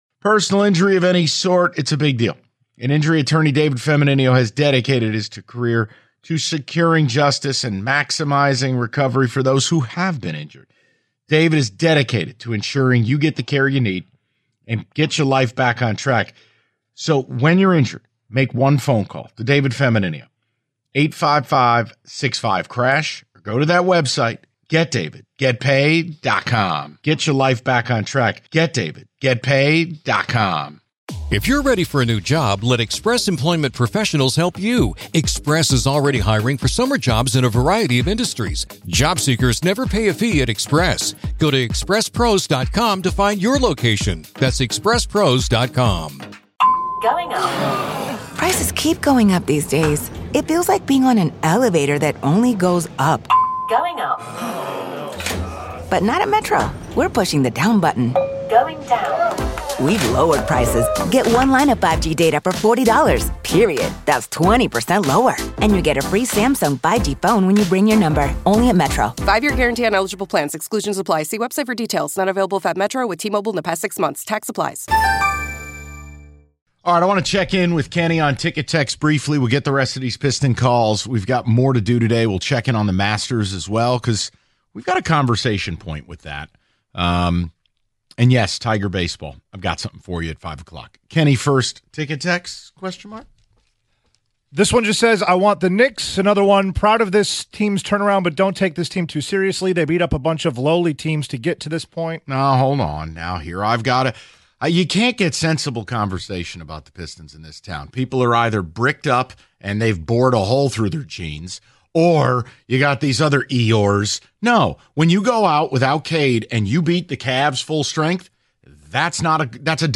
Taking Your Calls + Reading Your Ticket Texts On Pacers Or Knicks